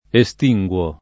estinguere [ e S t &jgU ere ]